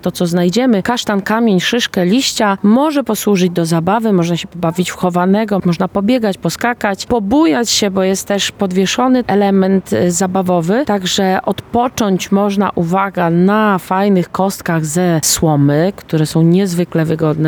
mówi w rozmowie z Radiem Lublin